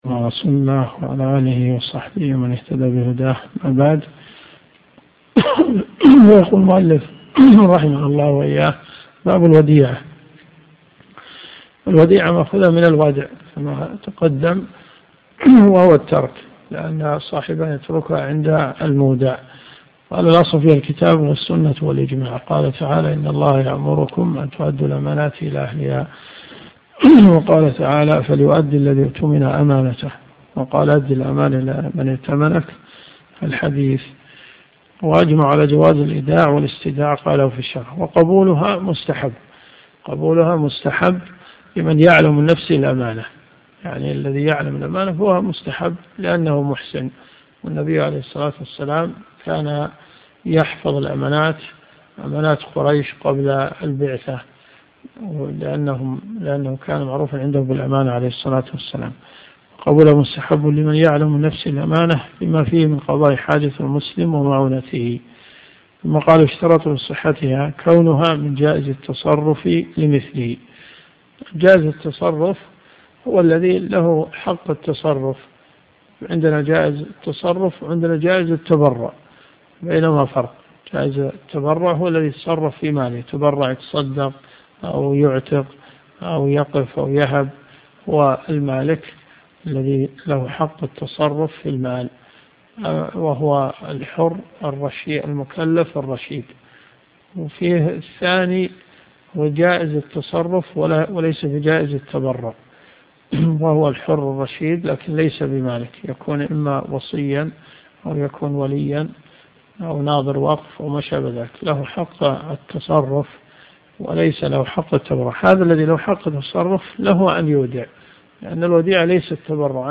الرئيسية الكتب المسموعة [ قسم الفقه ] > منار السبيل .